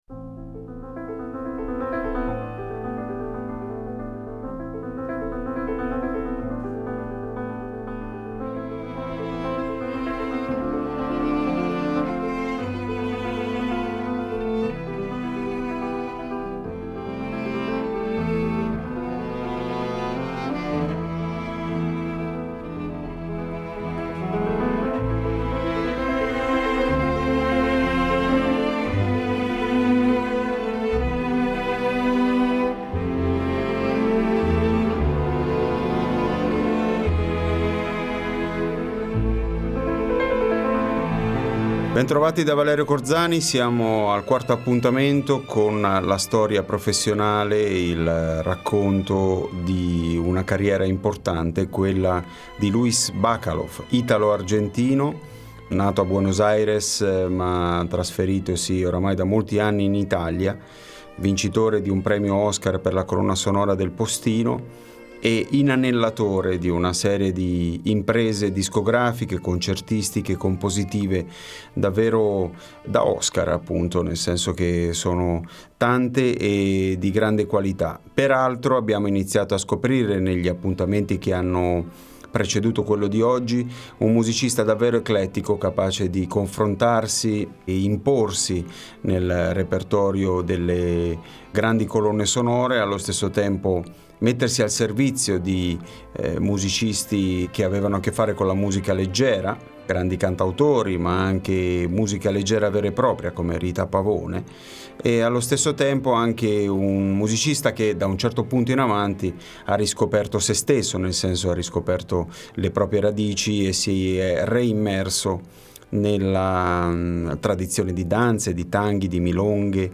Abbiamo incontrato Bacalov per farci raccontare idee sulla musica, aneddoti sulla sua carriera, influenze e incontri memorabili, rapporti con registi cinematografici e con il mercato musicale. Tra uno scambio di battute e l’altro ci guiderà lungo un tracciato sonoro inevitabilmente votato all’eclettismo, visto che ha lavorato con un buon numero di cantautori, con gruppi rock, con il cinema, con la musica cosiddetta “colta” e con i mille rivoli della cultura musicale sudamericana.